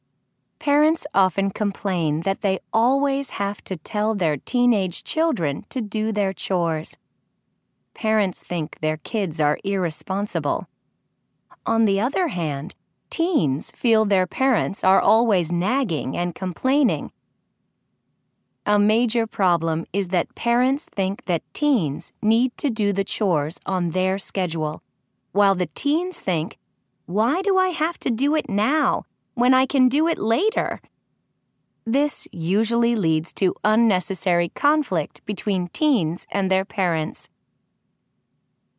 قطعه قرائيه جاهزه للصف الثاني الثانوي ف2 الوحده التاسعه mp3